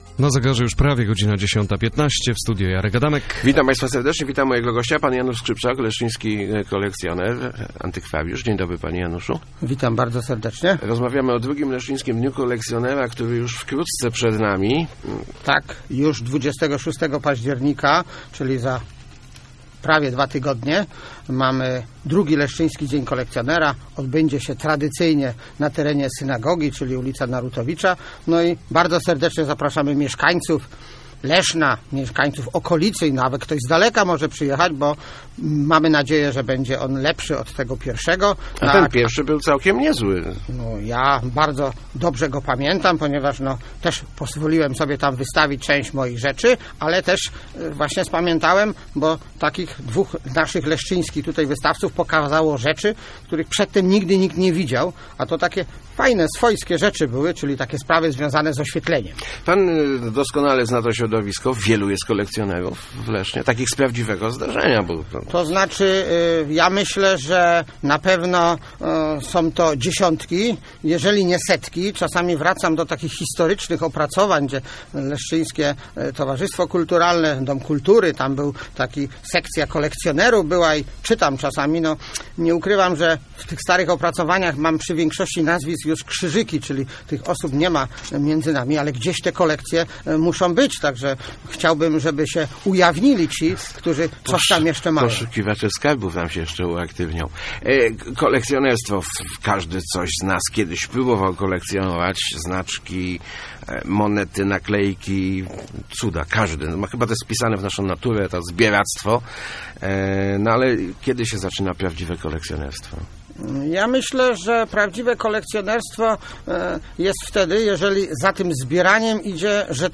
W Lesznie mamy dziesiątki kolekcjonerów - mówił w Rozmowach Elki